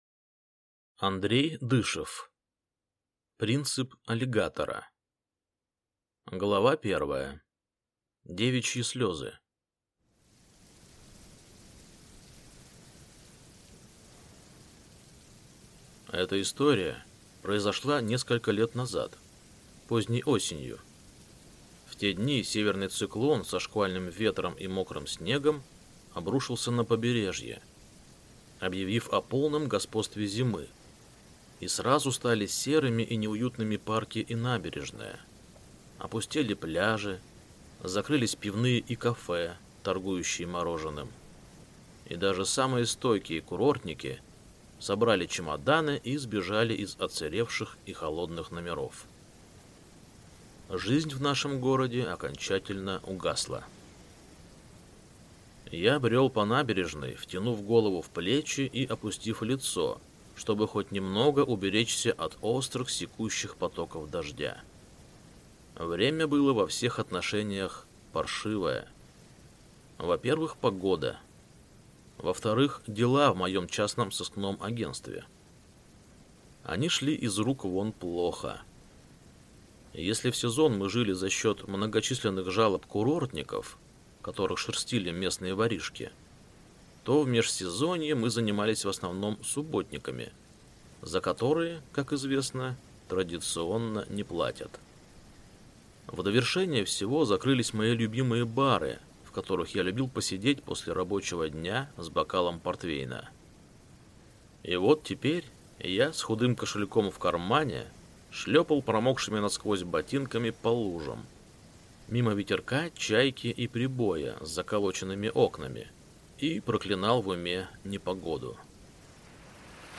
Аудиокнига Принцип аллигатора | Библиотека аудиокниг
Прослушать и бесплатно скачать фрагмент аудиокниги